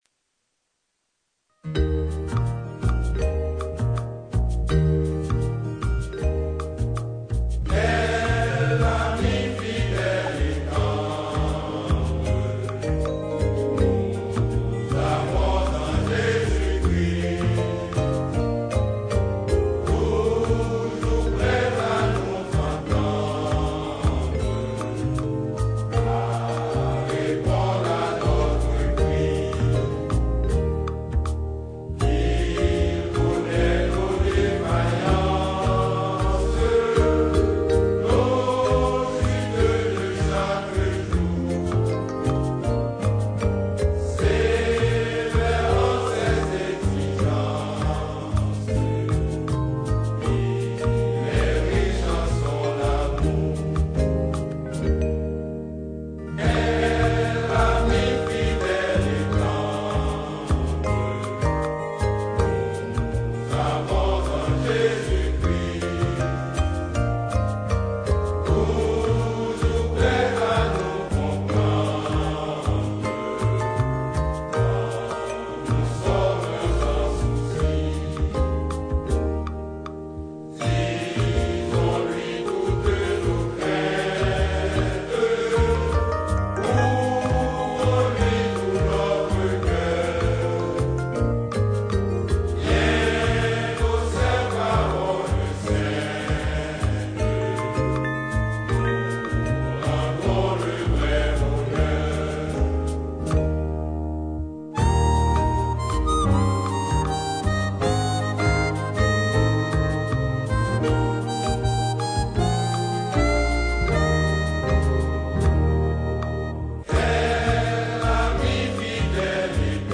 2022 CHANTS MYSTIQUES audio closed https